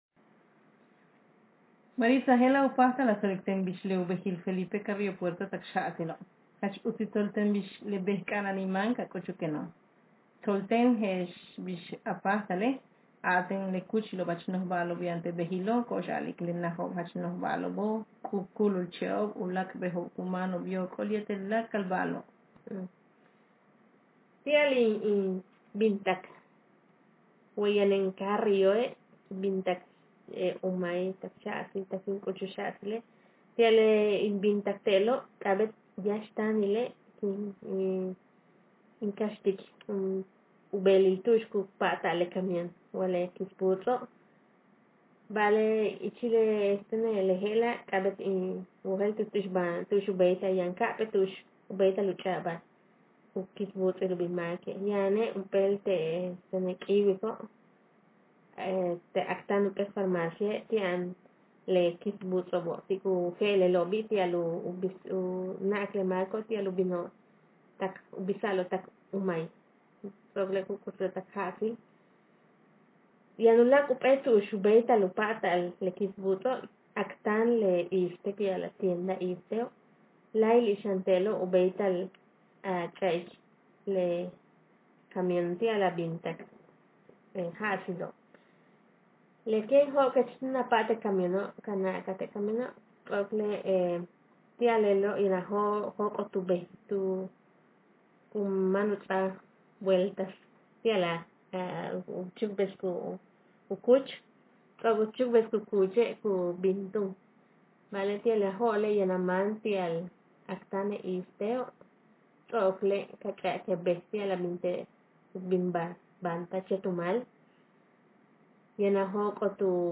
Speaker sexf
Text genreprocedural